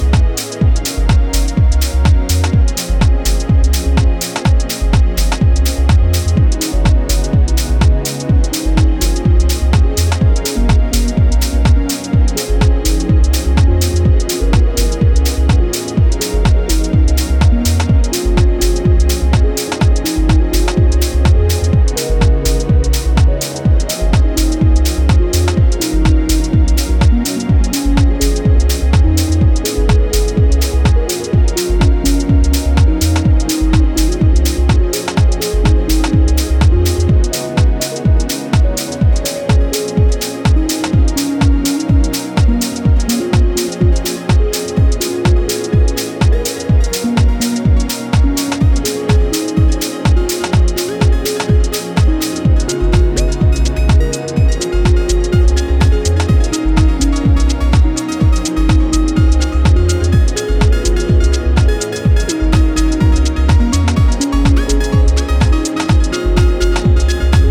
ジャンル(スタイル) DEEP HOUSE / TECH HOUSE